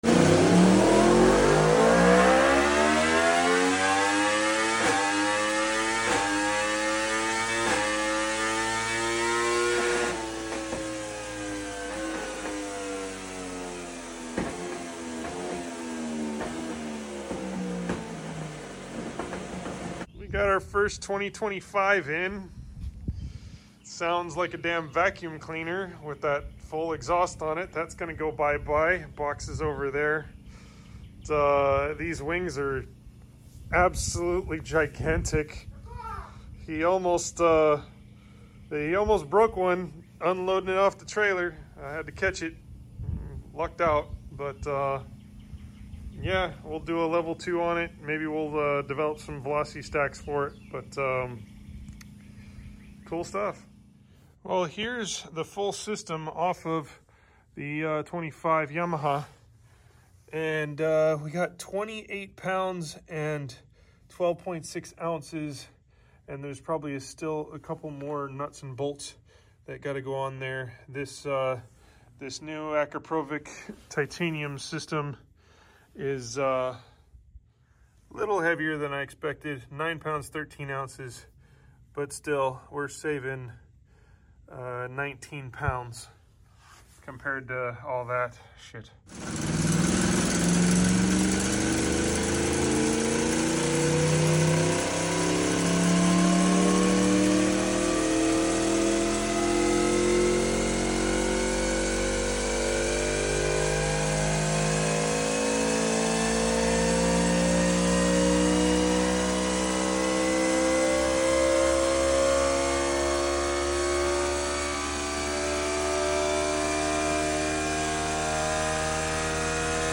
2025 Yamaha R1 Akro Full Sound Effects Free Download